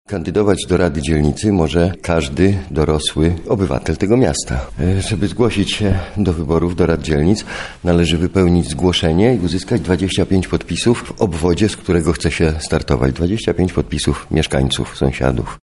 Kto i w jaki sposób może się jeszcze zgłosić? Wyjaśnia to Jarosław Pakuła, wiceprzewodniczący Rady Miasta Lublina